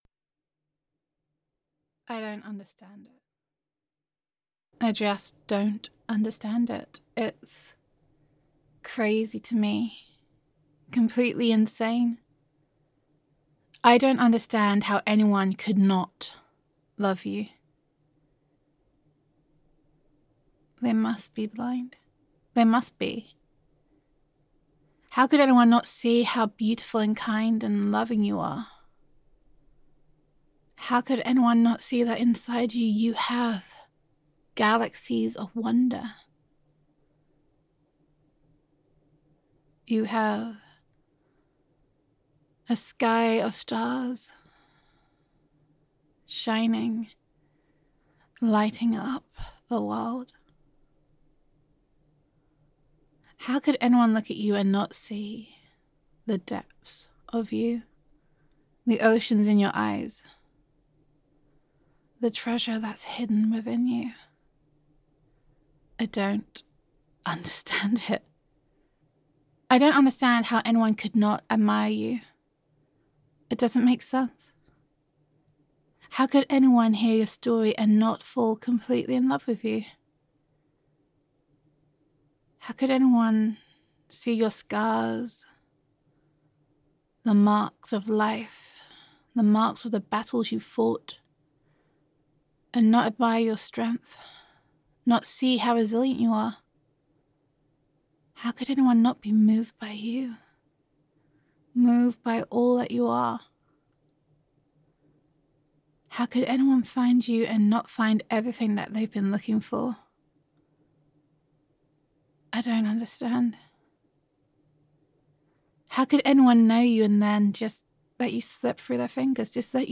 [F4A] I Don’t Understand How Anyone Could Not Love You [You Are Beautiful][You Are Lovable][You Are Deserving of Love][Gender Neutral][Comfort & Reassurance]